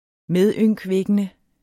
Udtale [ -ˌvεgənə ]